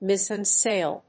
アクセントmízzen sàil